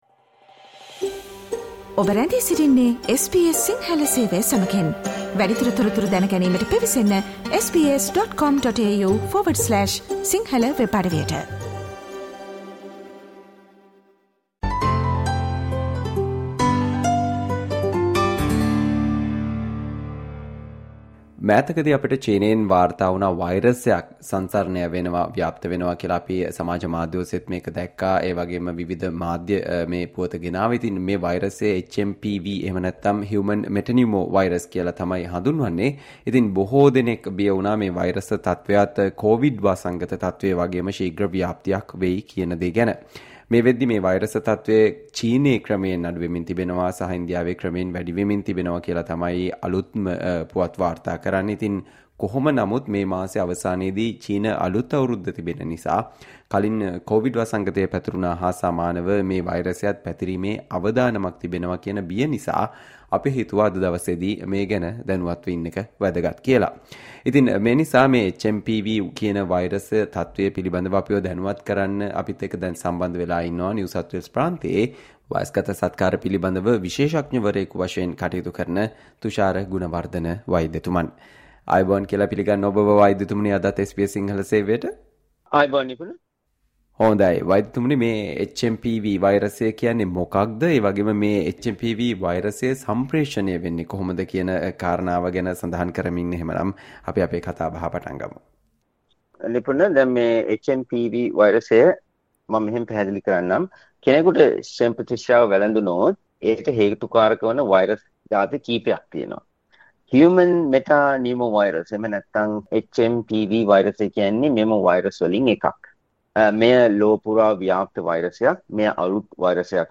Listen to the SBS Sinhala discussion on What you need to know about the recent outbreak of HMPV in China and why we should be concerned about this virus